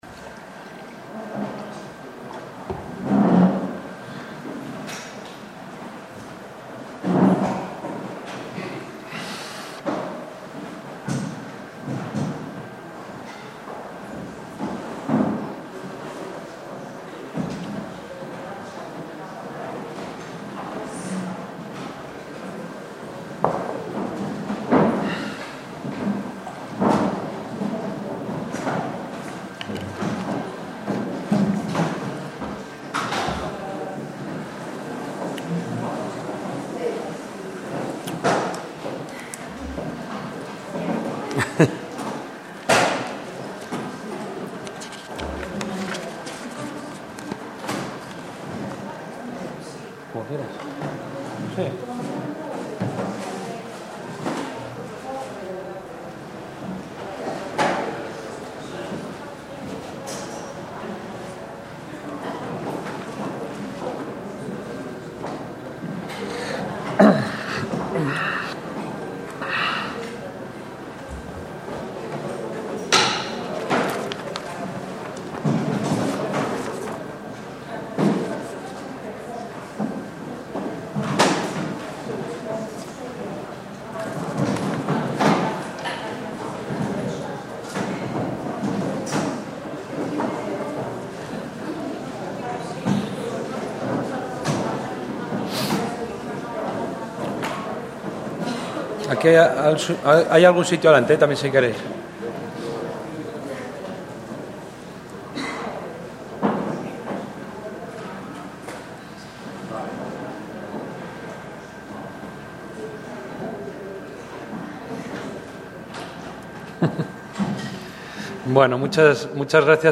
ARCHITECTURE LECTURE ON THE WORK OF EVA LOOTZ 'FORM FINDING'. FREI OTTO, A FREE MAN | Centro Galego de Arte Contemporánea